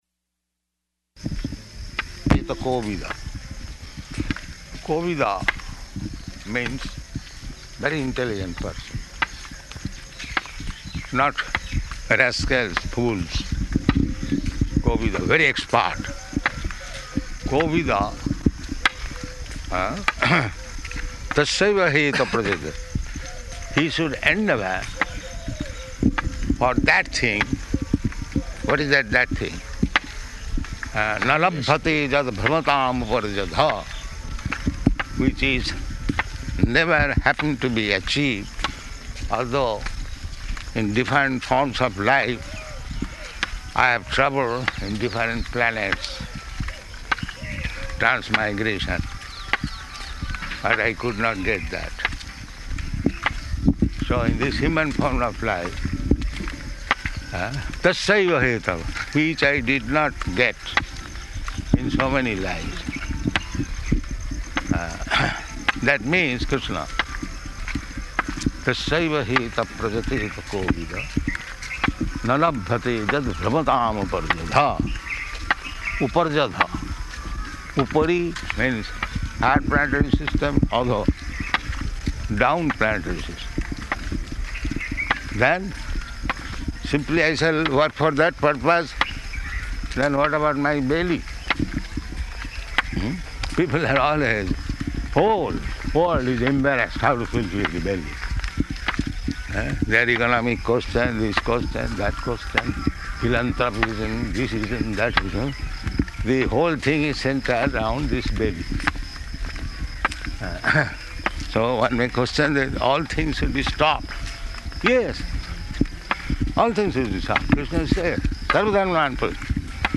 Type: Walk
Location: Vṛndāvana